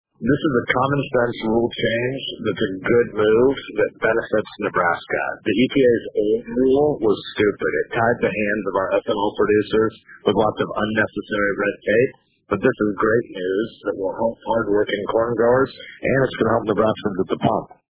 (AUDIO) Sasse Statement on E-15 Year-Round Rule Change